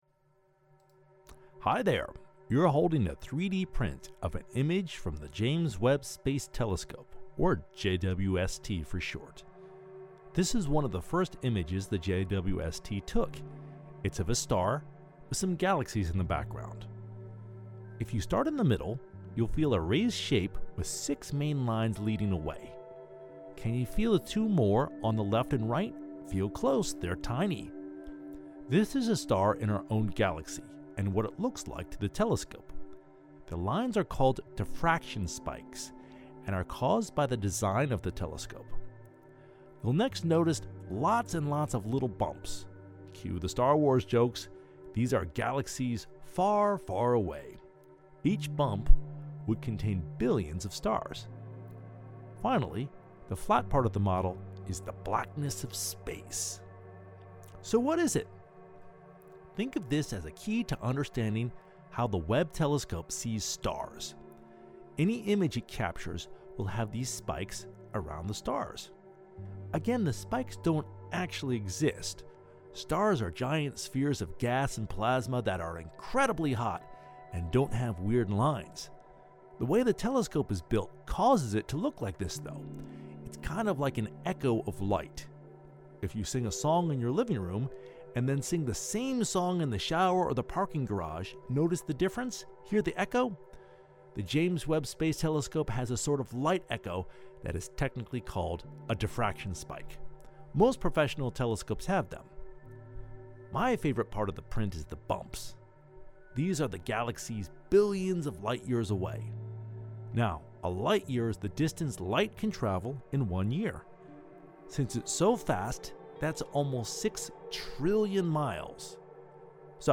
Audio Description of 3D Print
jwst_3dprint_voicedescription (1).mp3